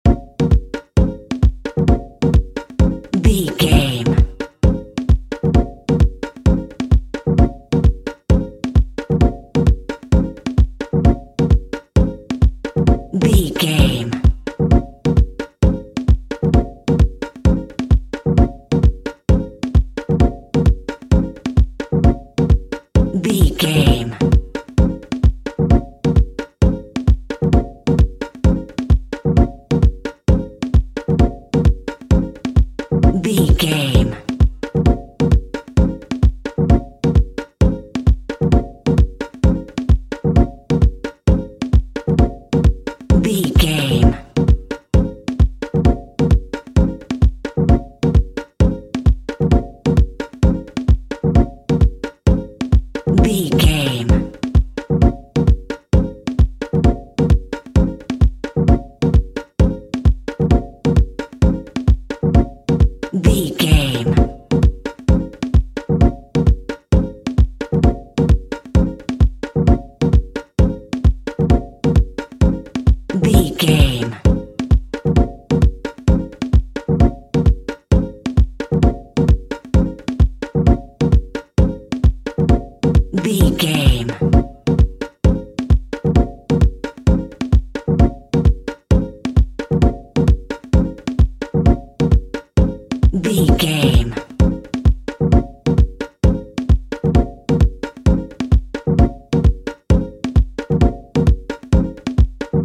Aeolian/Minor
B♭
groovy
happy
smooth
futuristic
hypnotic
drum machine
synthesiser
house
techno
electro house
synth leads
synth bass